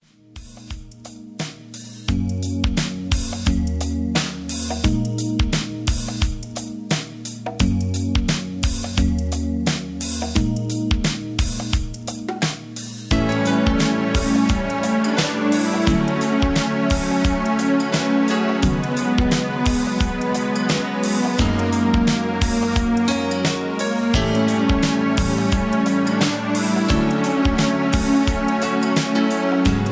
(Version-2, Key-Gb) Karaoke MP3 Backing Tracks